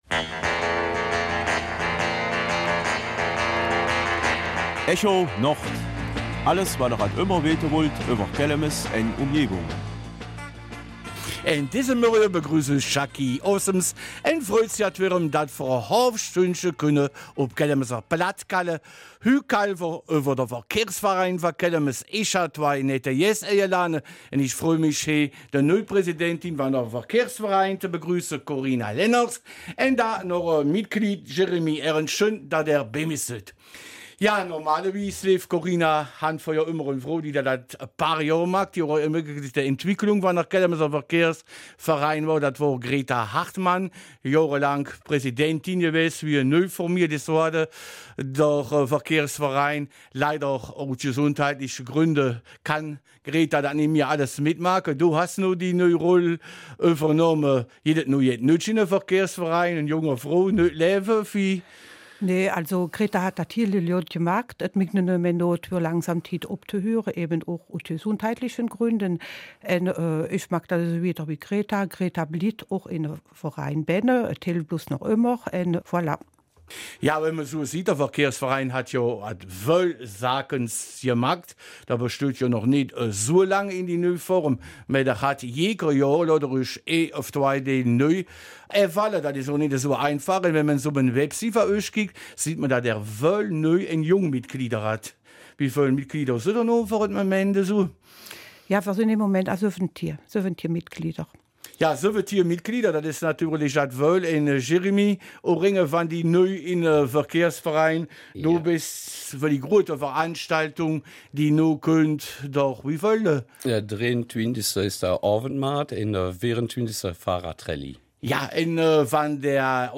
Kelmiser Mundart - 17.
Gäste im Studio.